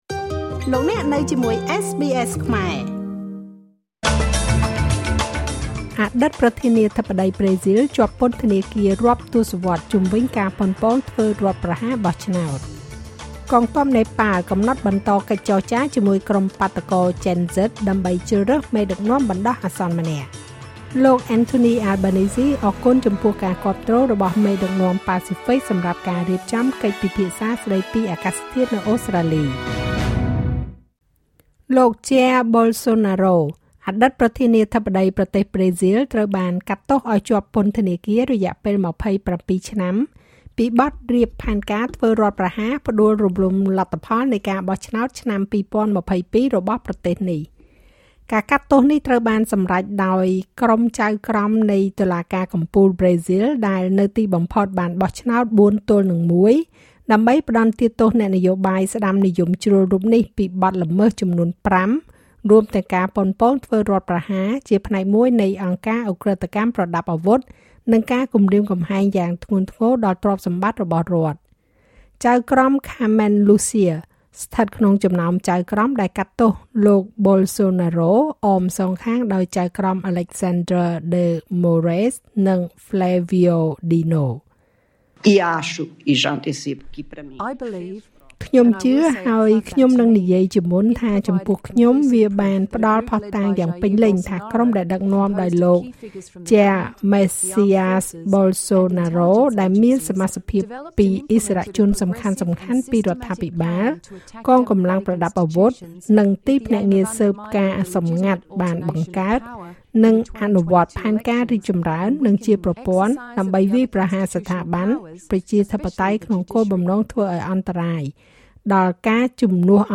នាទីព័ត៌មានរបស់SBSខ្មែរ សម្រាប់ថ្ងៃសុក្រ ទី១២ ខែកញ្ញា ឆ្នាំ២០២៥